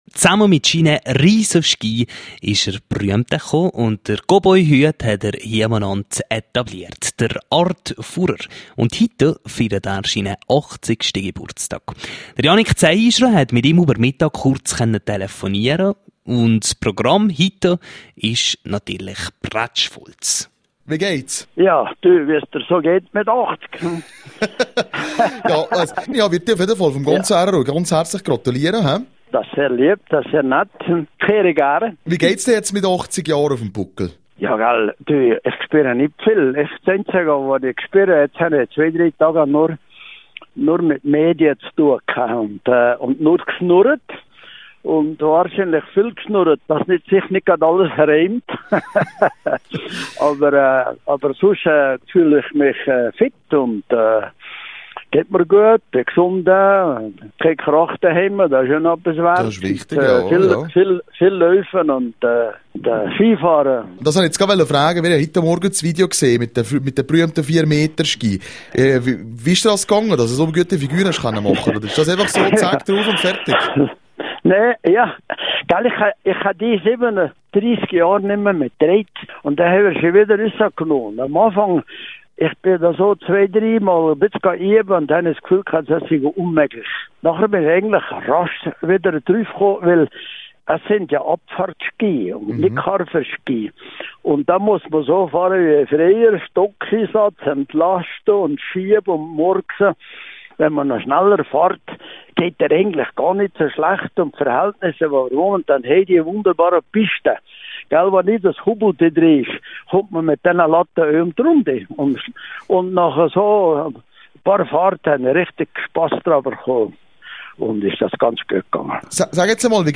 Hut ab./bj Das Geburtstagsinterview mit Art Furrer zu seinem Achtzigsten (Quelle: rro)